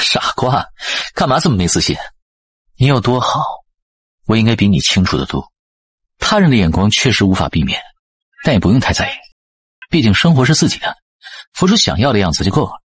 感動的でモチベーショナルなAIナレーション
テキスト読み上げ
高揚感のあるトーン
モチベーショナルスピーチ
インパクトのあるメッセージングに合わせた自然な抑揚と表現力豊かな間が特徴です。